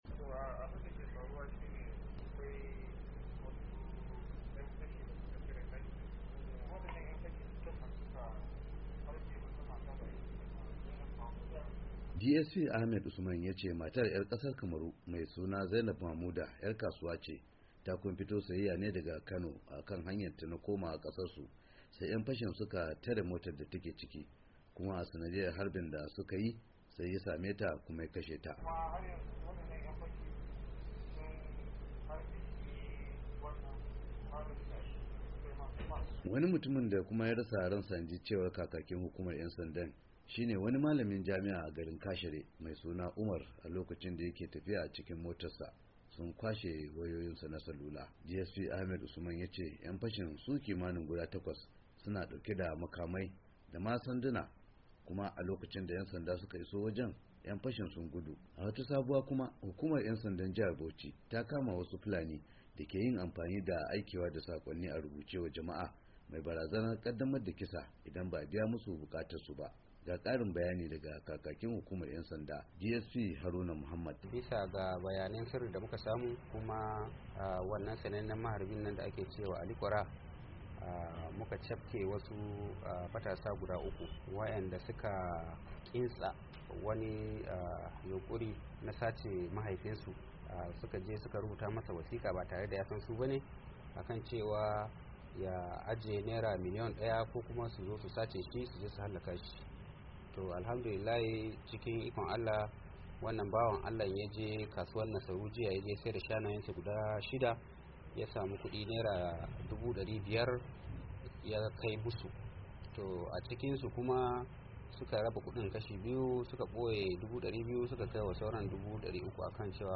Ga cikkaken rahoton.